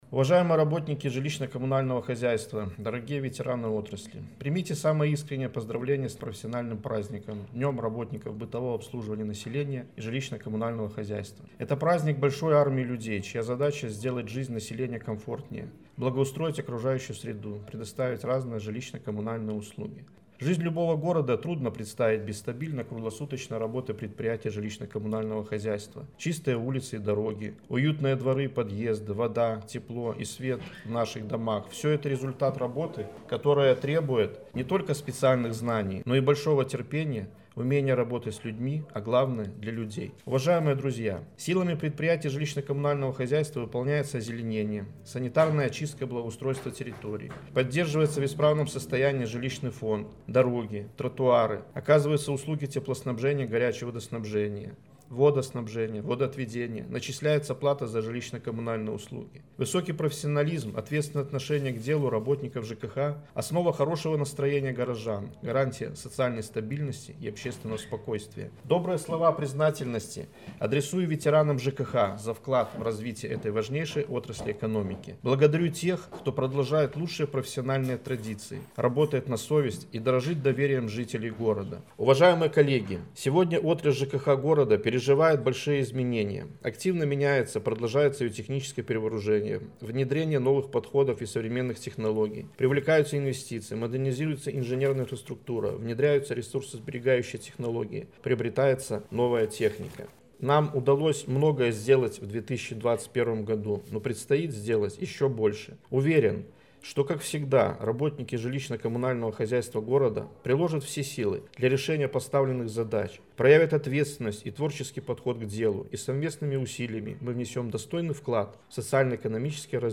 В преддверии значимой даты на базе музыкальной школы искусств состоялась торжественная церемония чествования лучших специалистов.